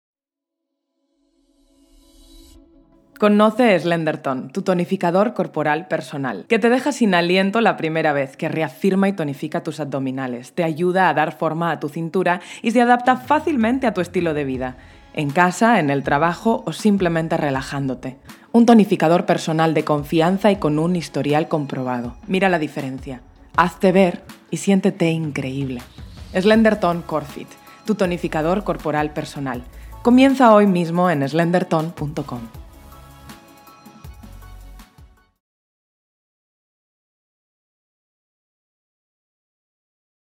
Versatile, naturally mature, essentially deep, inspiring, exciting, creative, surprising voice!
kastilisch
Sprechprobe: Werbung (Muttersprache):
Vocal age between 15 and 50 years.